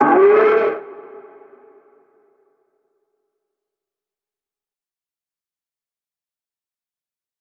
DMV3_Vox 12.wav